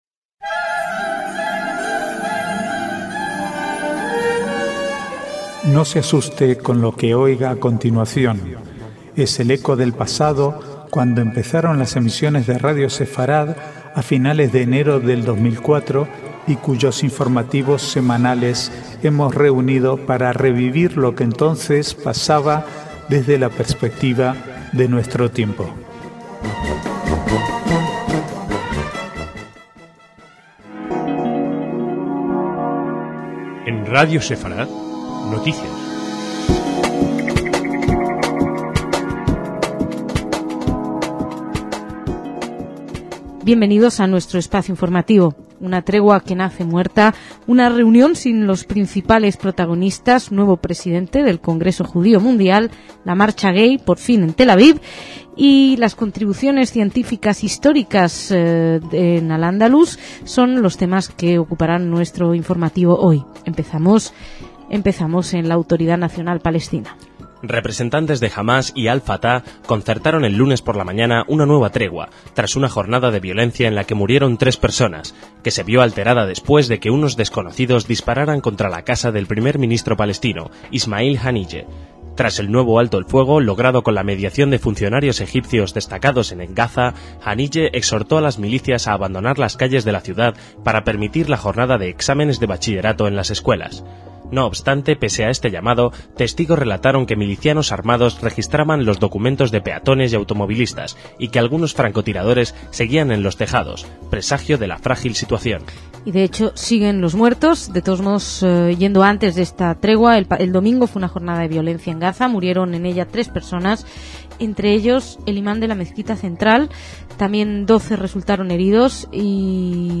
Archivo de noticias del 12 al 14/6/2007